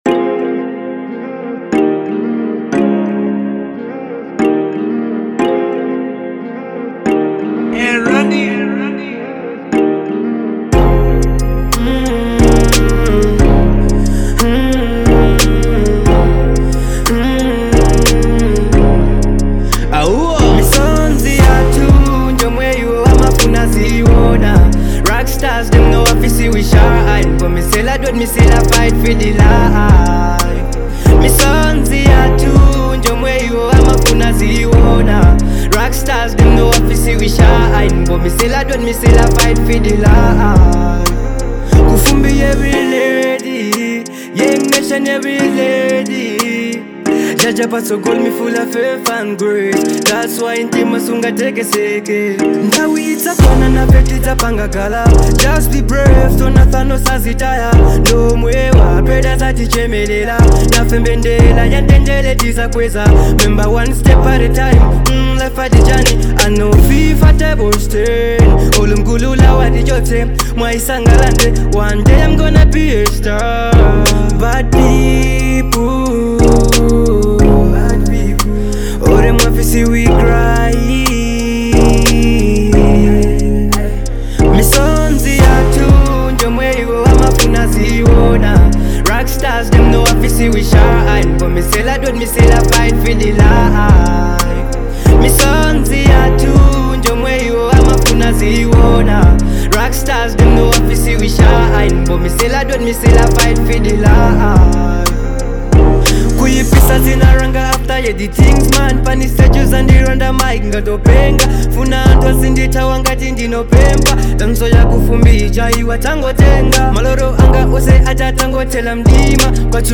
Genre : Dancehall